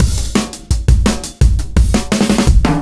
cw_170_BreakingUp3.wav